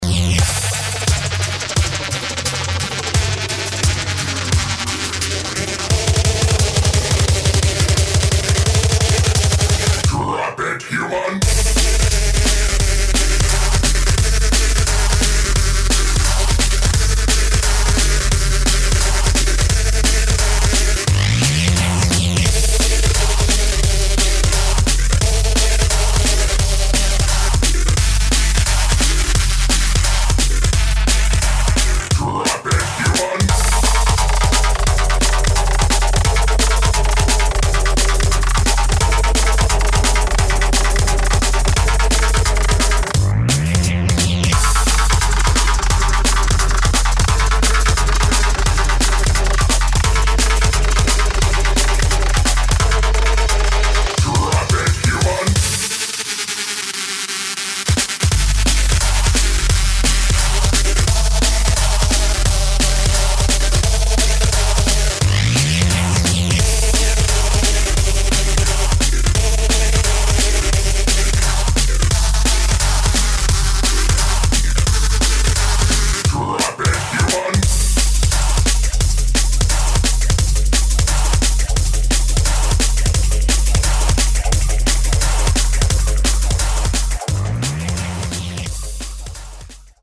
DRUM'N'BASS | JUNGLE